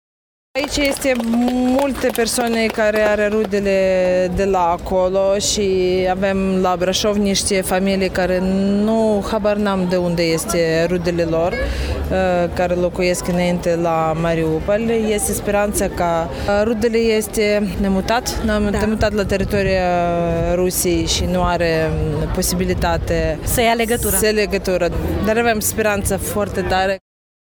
Zeci de ucraineni refugiaţii la Braşov au participat ieri la un miting de susţinere a militarilor ucraineni reţinuţi în Rusia. Participanții au declarat că au rude şi prieteni despre care nu mai ştiu nimic: